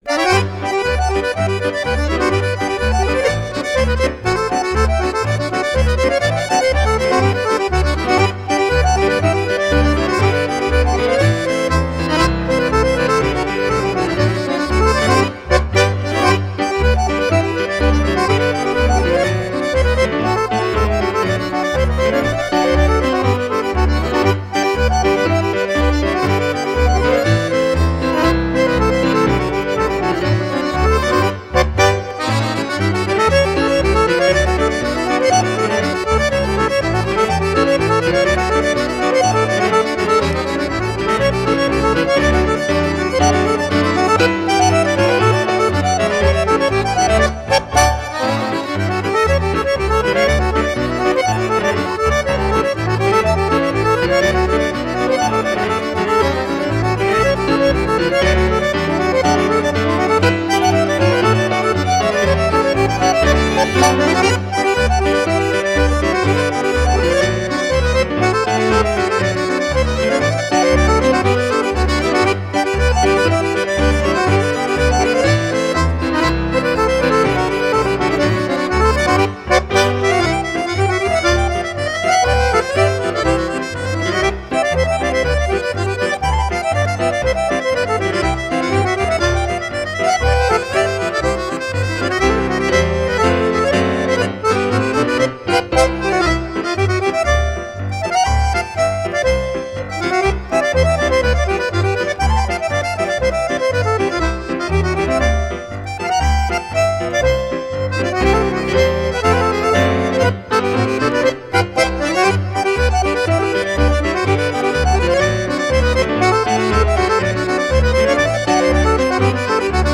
Handorgelduo
Zugerbärgholzer. Schottisch.